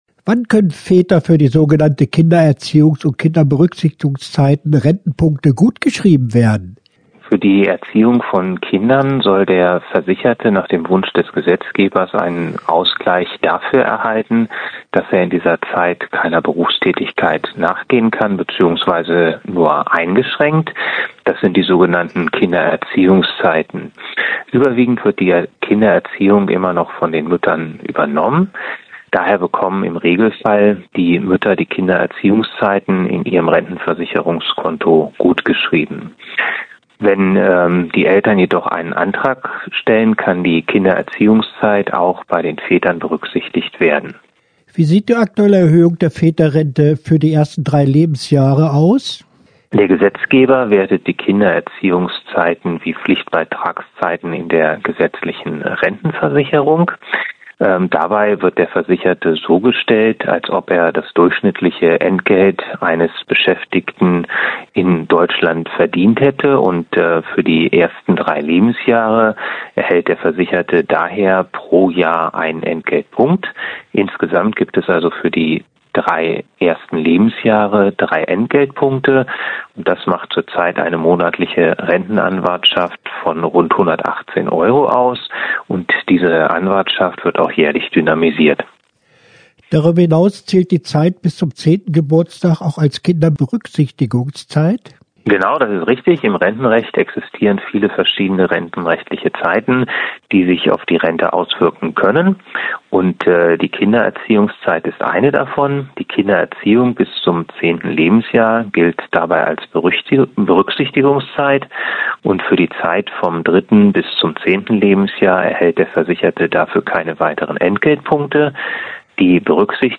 Interview-Rentenpunkte-fuer-Erziehungszeiten.mp3